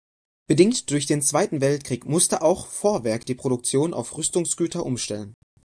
Pronounced as (IPA)
/ʊmˈʃtɛlən/